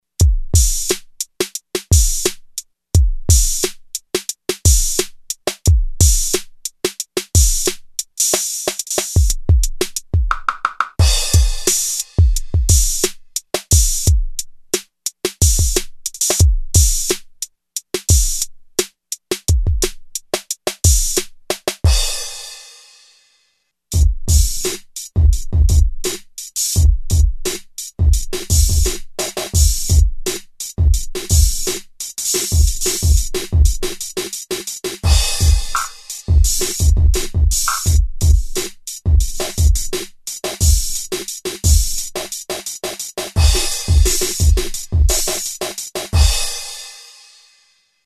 I used a Roland JV-2080 (expanded) with its internal effects turned off.
The dry sound is played first, followed by the processed version.
05 EARLY REF. 1 Typical '80s, and very good!  Warm and nice.
05 EARLY REF. 1.mp3